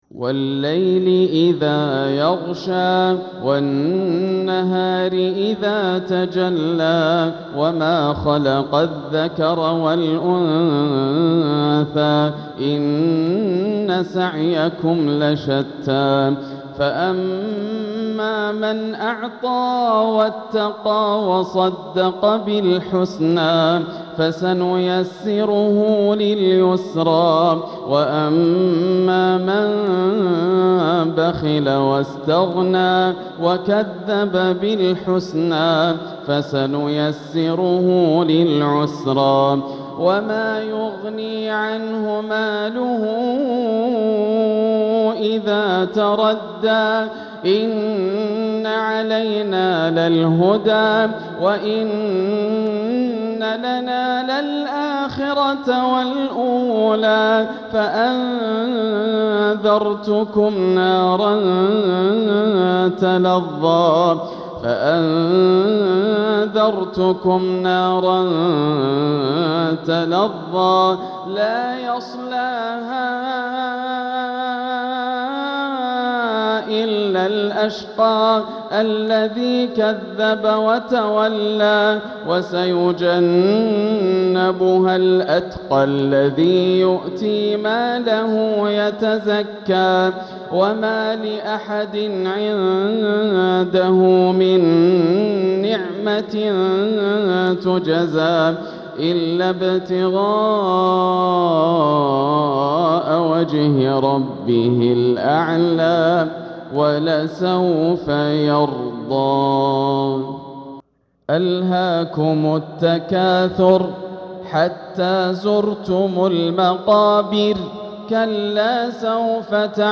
تلاوة لسورتي الليل والتكاثر | عشاء الأحد 2-2-1447هـ > عام 1447 > الفروض - تلاوات ياسر الدوسري